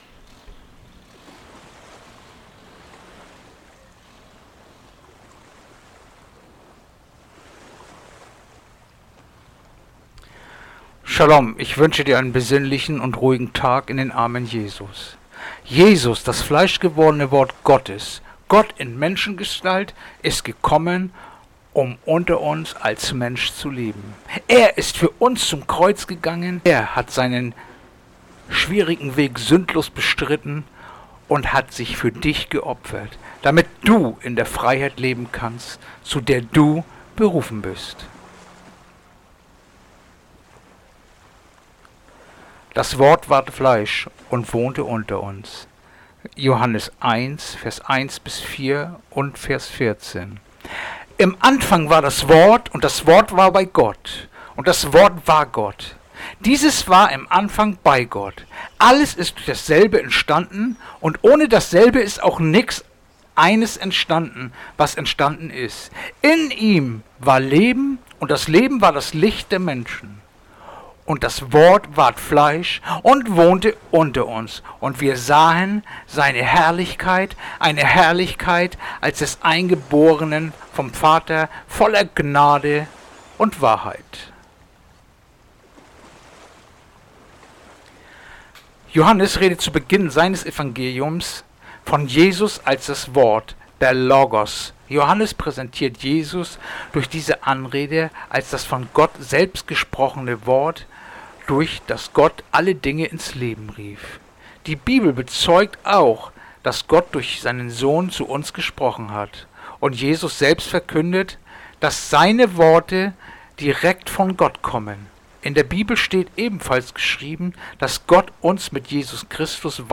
Andacht-vom-03-Juli-Johannes-1-1-4-14